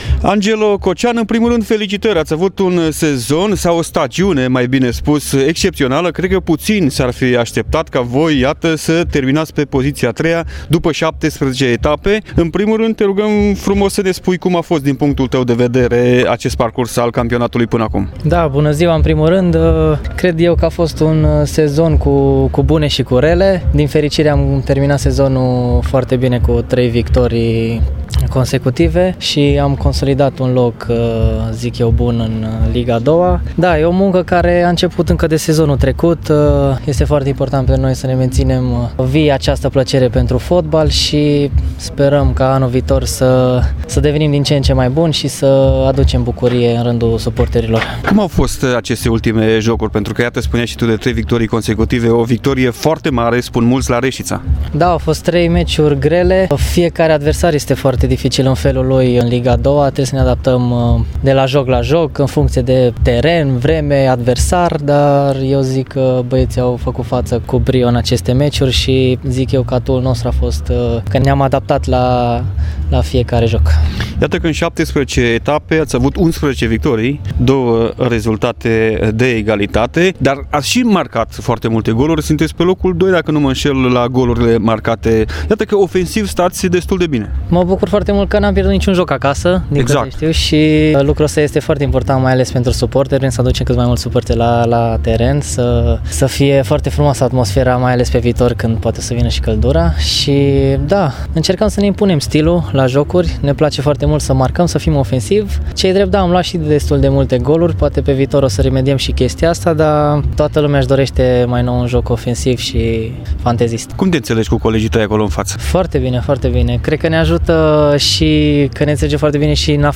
Interviul complet